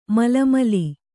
♪ mala mali